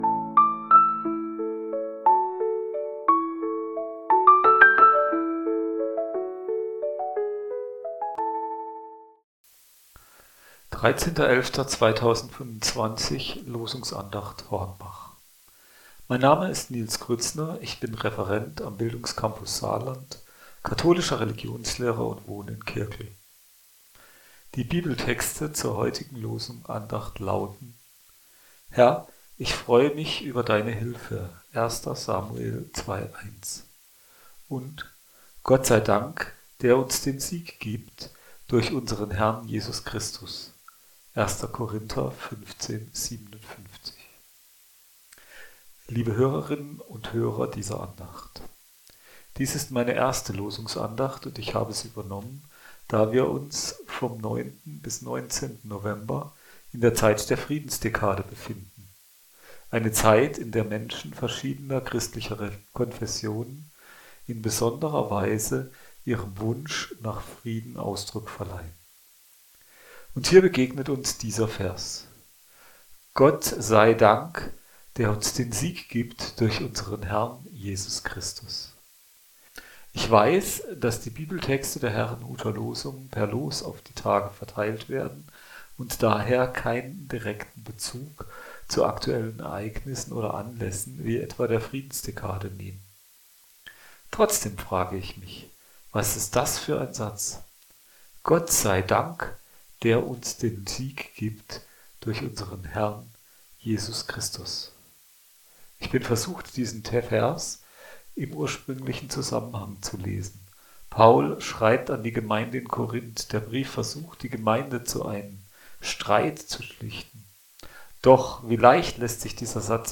Losungsandacht für Donnerstag, 13.11.2025